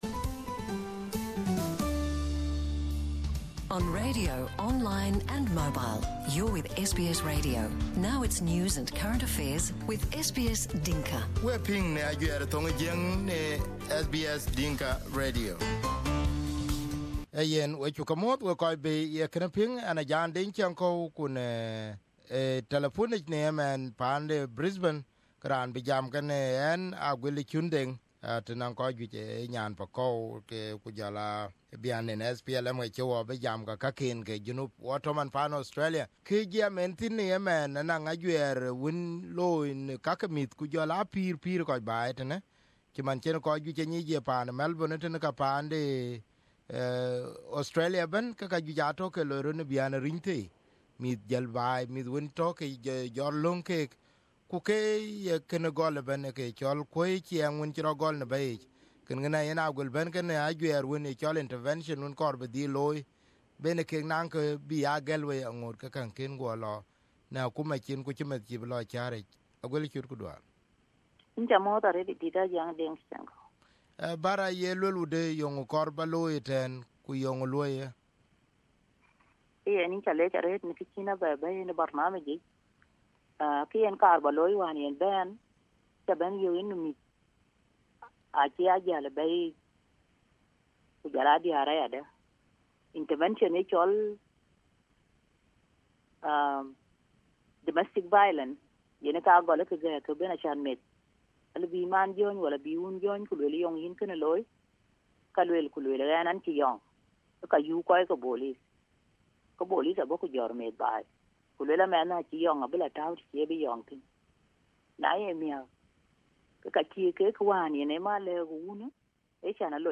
This was the interview